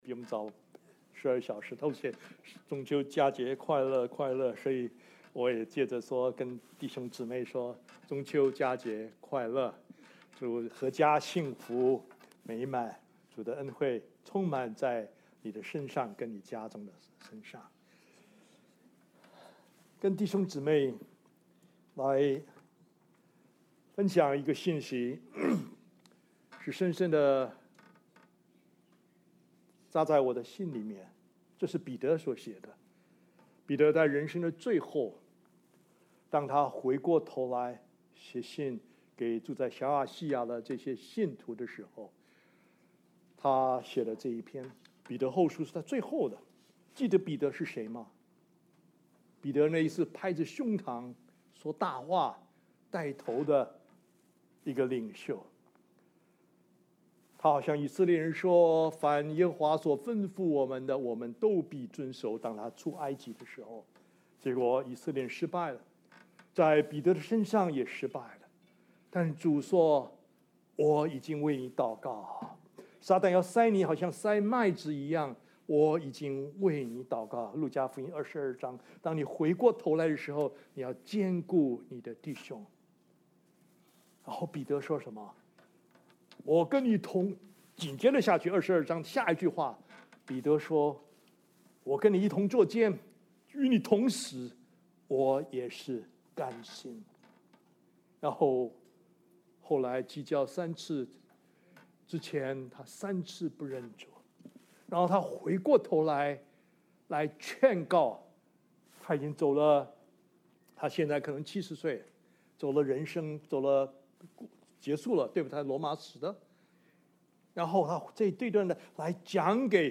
Passage: 2 Peter 1:3–11 Service Type: 主日证道 Download Files Notes « 雙重明亮的太陽 禿子和母熊的故事 » Submit a Comment Cancel reply Your email address will not be published.